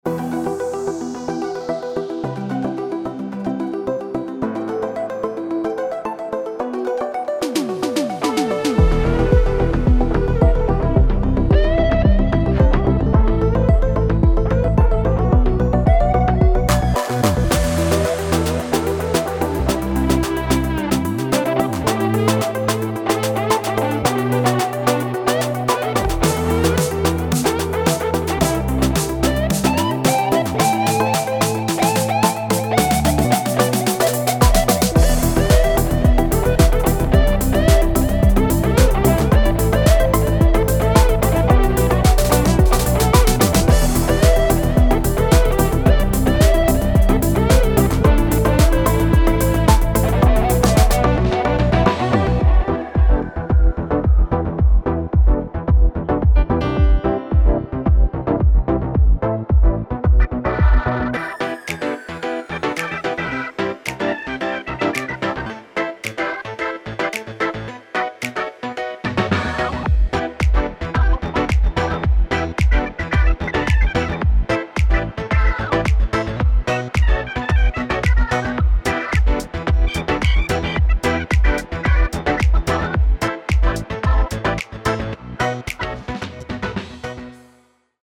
06 Slow
אסטה-אלקטרוני_03.mp3